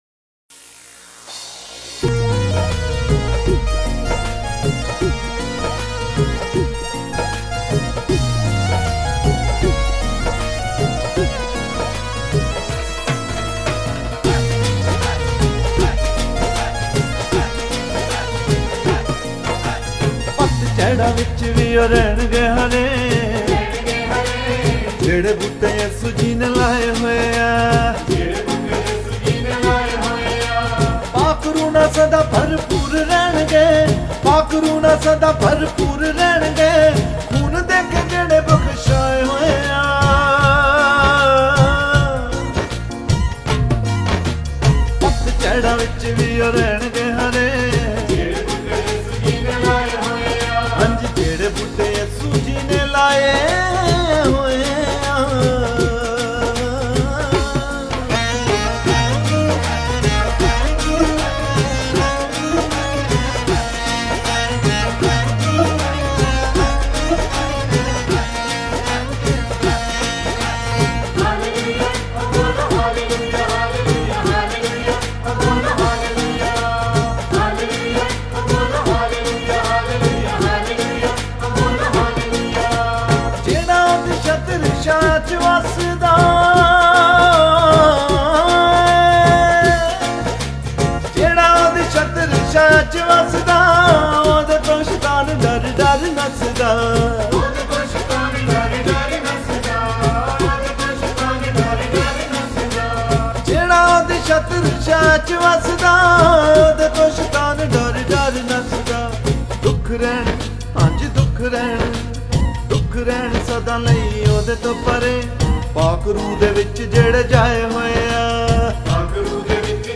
Listen and download Gospel songs